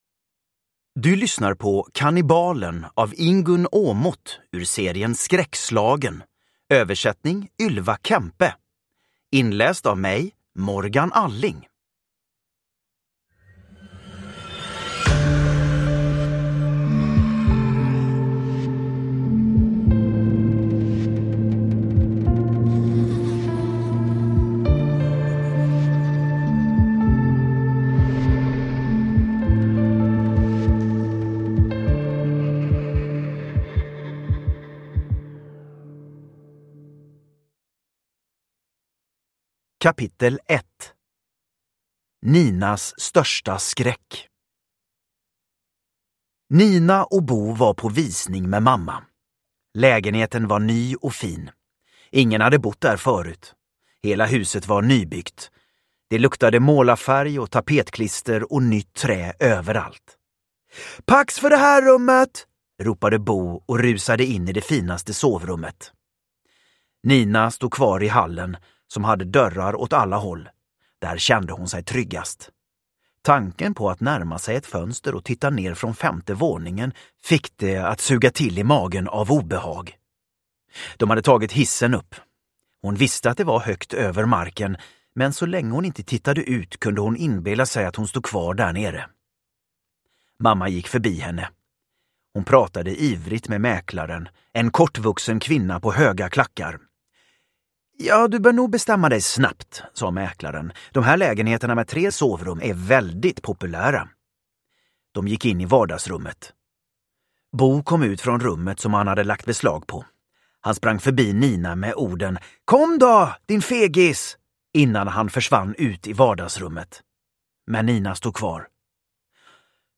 Kannibalen – Ljudbok – Laddas ner
Uppläsare: Morgan Alling